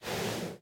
sounds / mob / horse / breathe2.mp3
breathe2.mp3